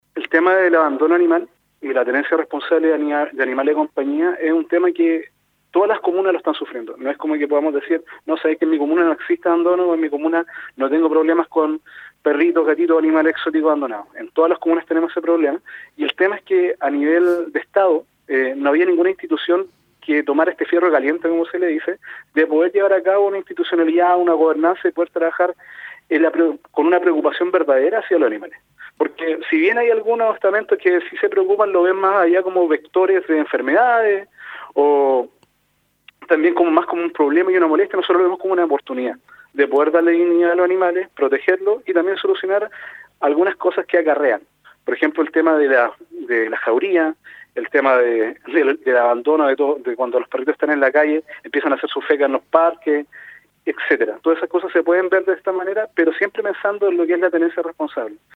En entrevista con Radio UdeC, destacó el carácter pionero a nivel nacional que tendrá esta iniciativa.